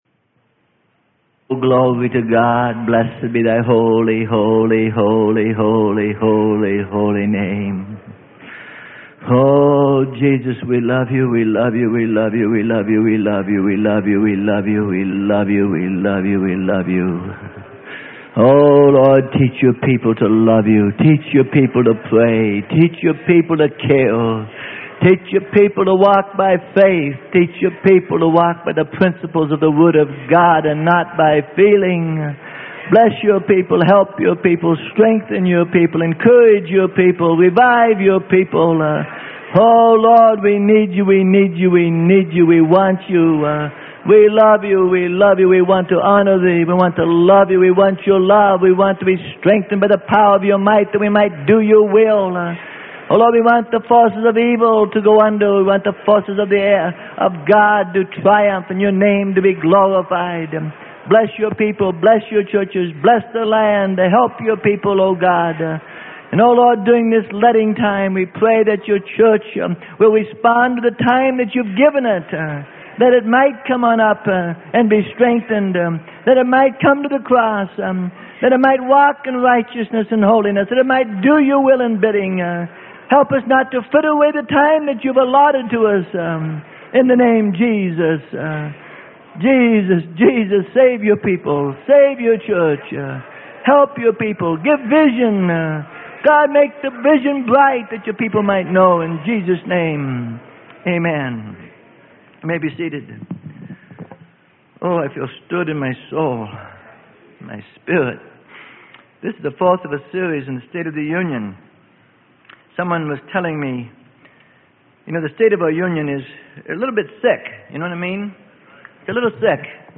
Sermon: 1984 STATE OF THE UNION ADDRESS - PART 4 OF 4 - Freely Given Online Library